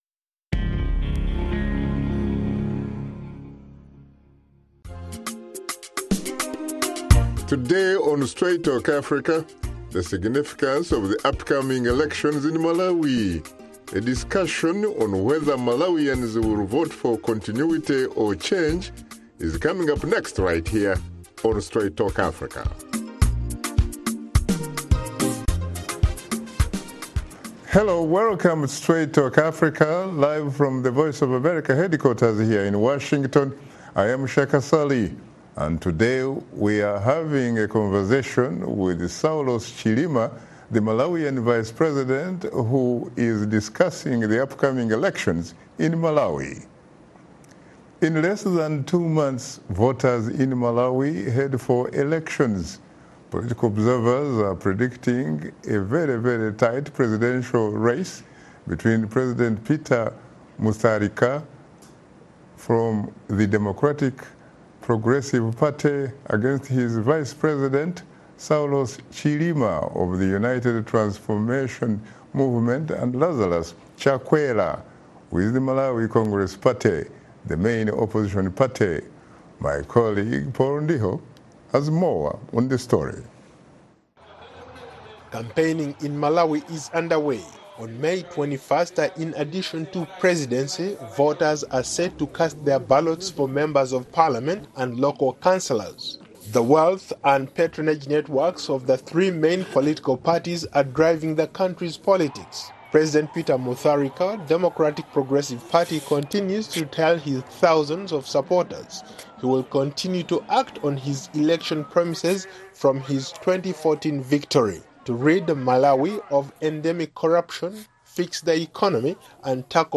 In the episode of Straight Talk Africa host Shaka Ssali talks to Saulos Chilima Vice President of Malawi, who is also a presidential candidate representing the opposition group, the United Transformation Movement, and is challenging incumbent President, Arthur Peter Mutharika.